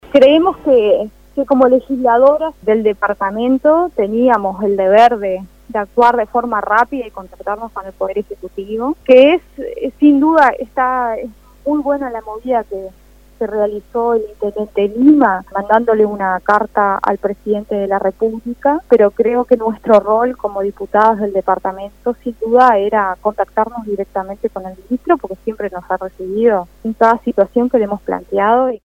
Manuela Mutti, diputada del Frente Amplio, dijo en Índice 810 que tenían "el deber de actuar rápido" por una cuestión de "responsabilidad política".